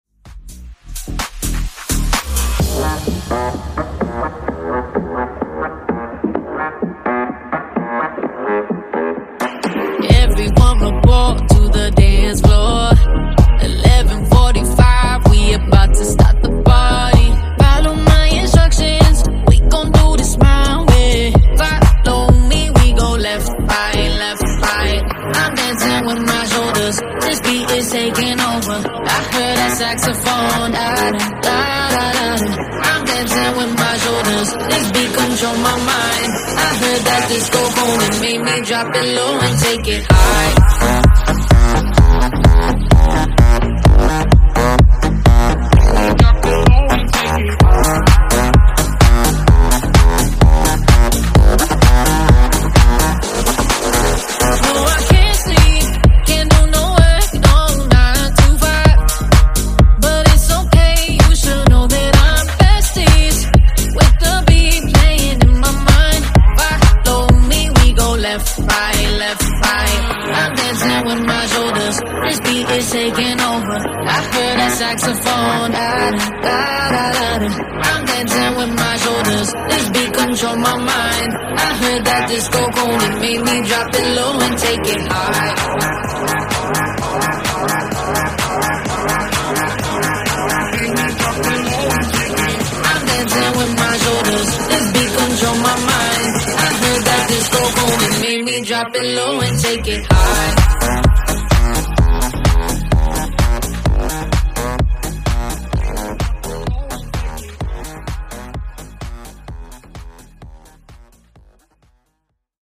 Genres: RE-DRUM , REGGAETON
Clean BPM: 97 Time